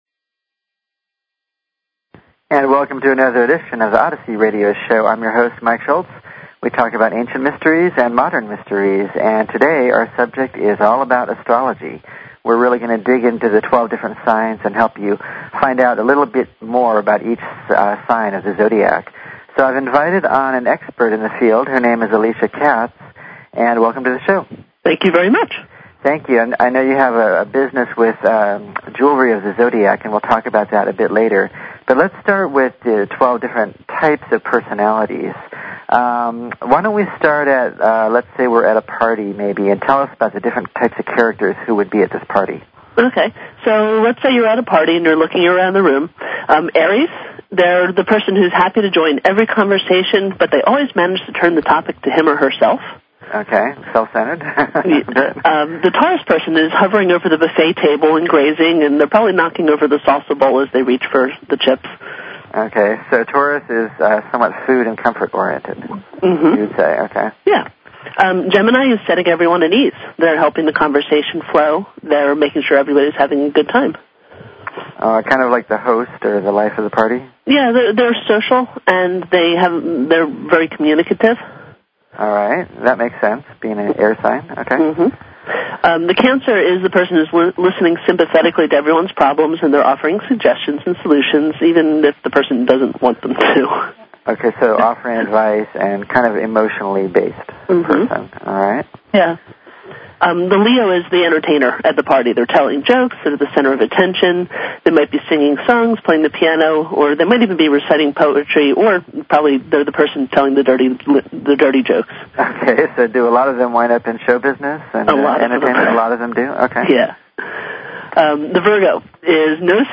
Talk Show Episode, Audio Podcast, Odyssey and Courtesy of BBS Radio on , show guests , about , categorized as